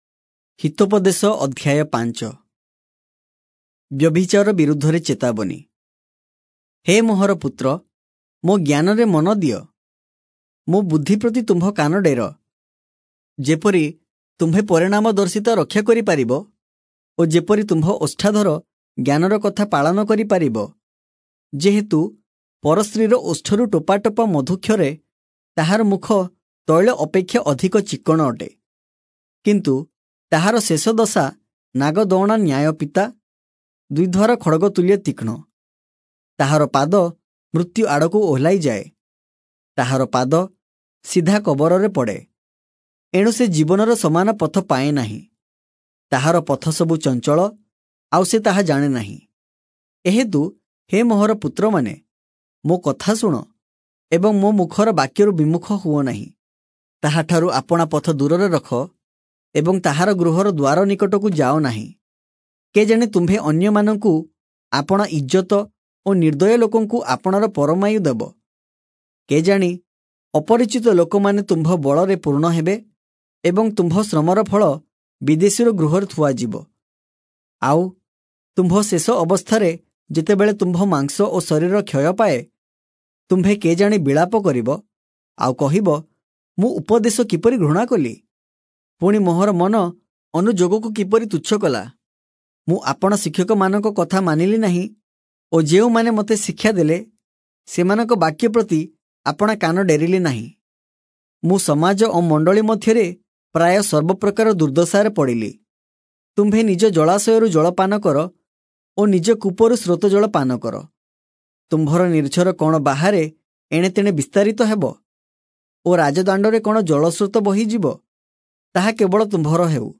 Oriya Audio Bible - Proverbs 12 in Irvor bible version